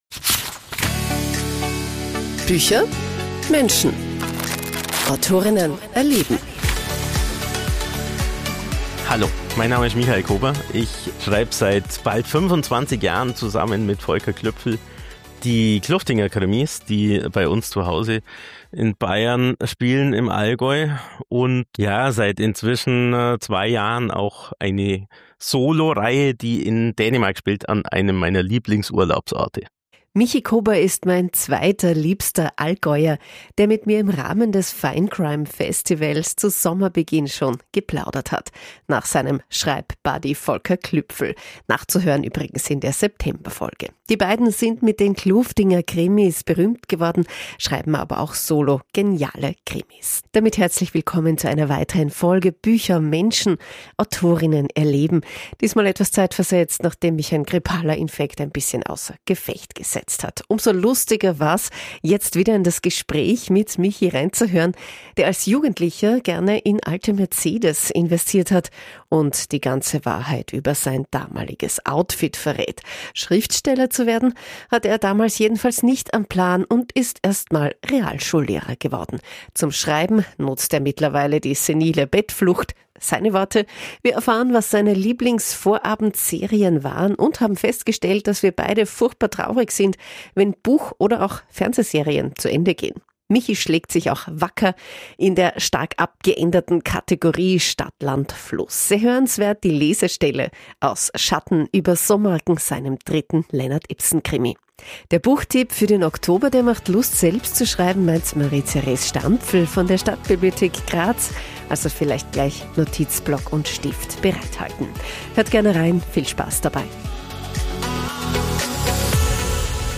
Beschreibung vor 6 Monaten Michi Kobr ist mein zweiter liebster Allgäuer, der mit mir ihm Rahmen des finecrime Festivals zu Sommerbeginn geplaudert hat – nach seinem Schreibbuddy Volker Klüpfel – nachzuhören in der September Folge!
Sehr hörenswert – die Lesestelle aus Schatten über Somarken, seinem dritten Lennart Ipsen Krimi.